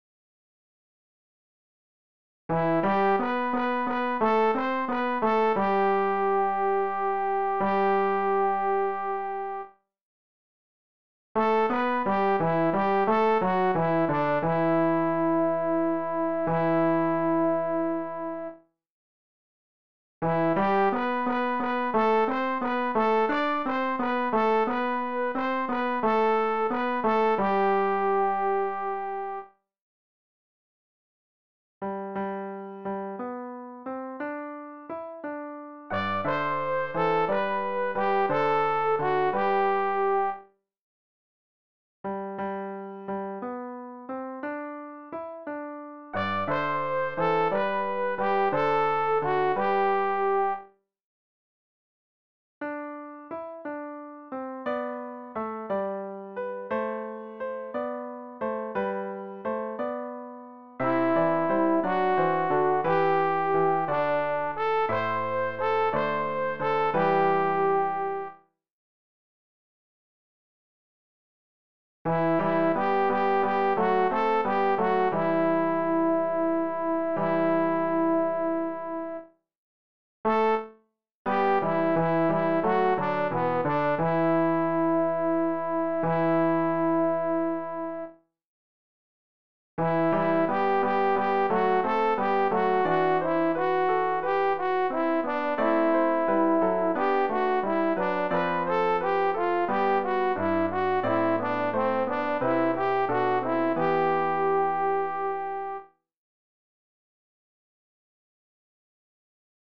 Alt